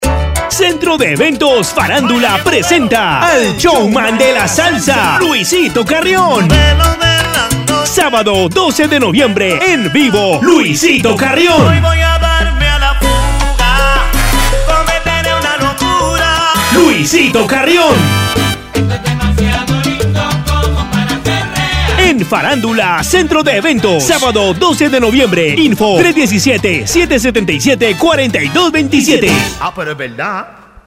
Voz comercial para radio